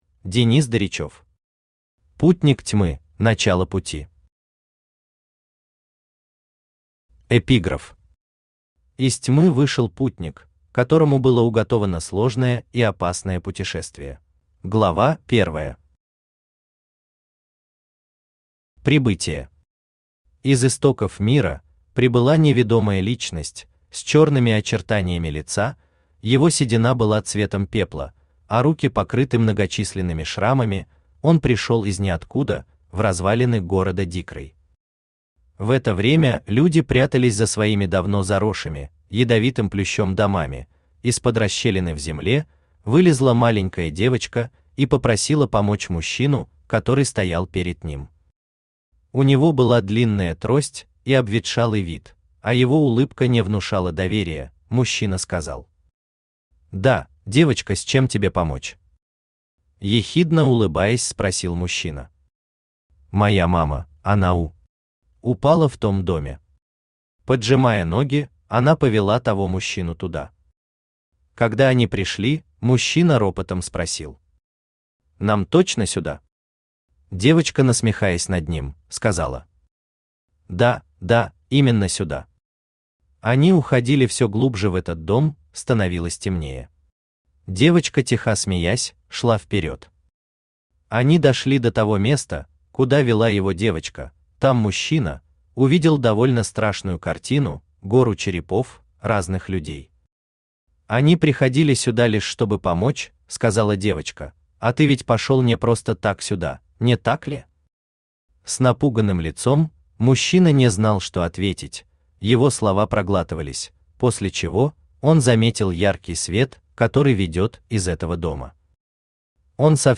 Аудиокнига Путник тьмы: Начало пути | Библиотека аудиокниг
Aудиокнига Путник тьмы: Начало пути Автор Денис Сергеевич Даричев Читает аудиокнигу Авточтец ЛитРес.